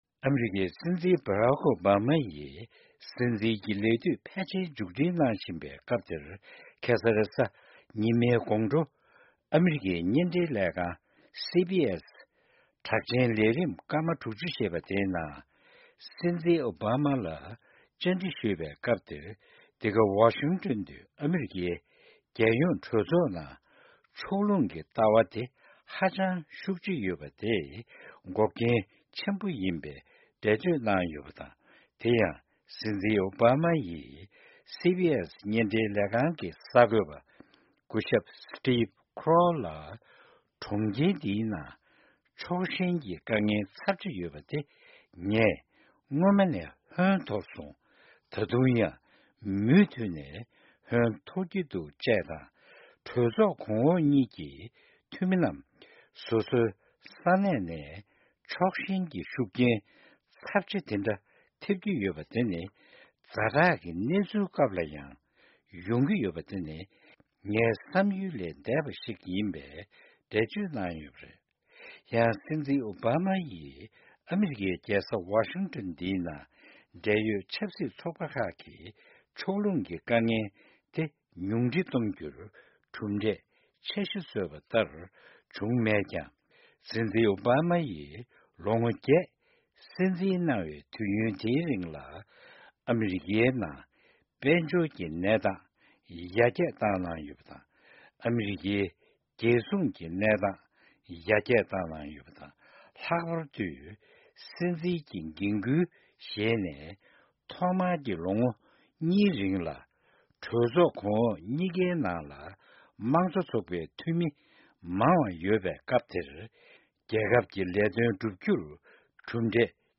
ཨ་མི་རི་ཀའི་སྲིད་འཛིན་ཨོ་བྷ་མ་བཅར་འདྲི།